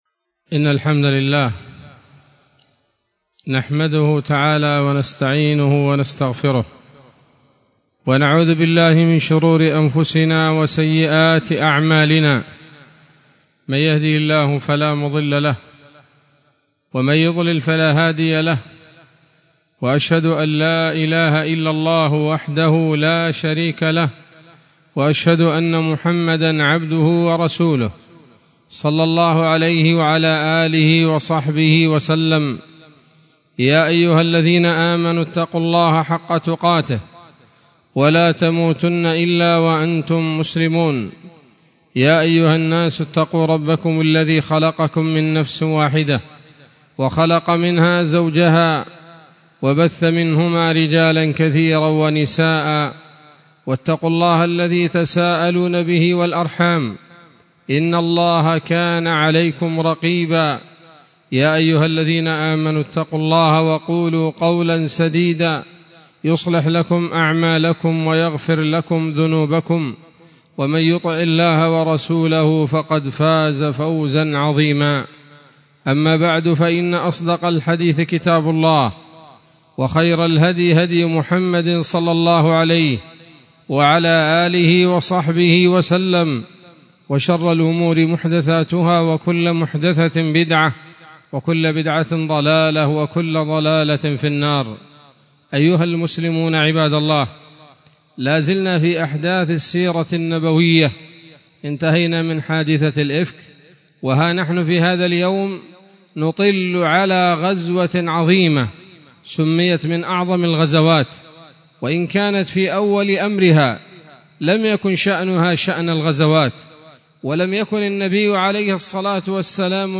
خطبة جمعة بعنوان: (( السيرة النبوية [25] )) 29 ذي الحجة 1445 هـ، دار الحديث السلفية بصلاح الدين